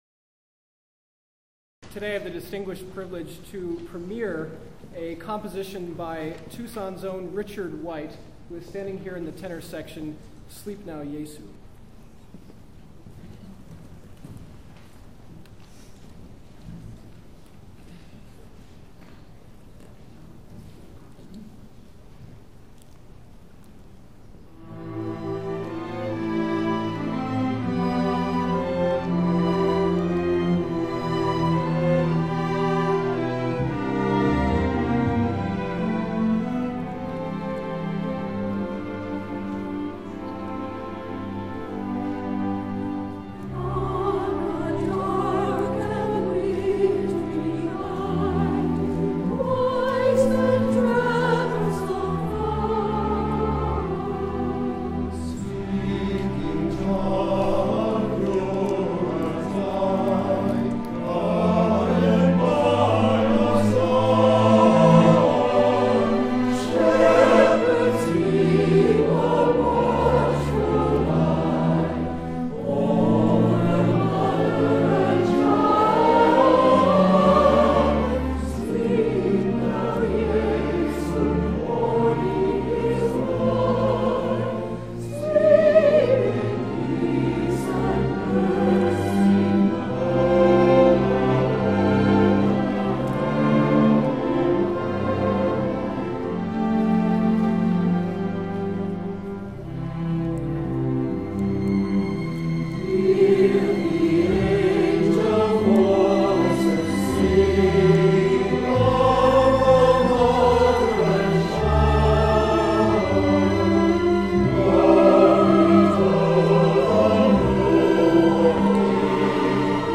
SATB and Strings. Live performance